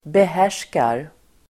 Uttal: [beh'är_s:kar]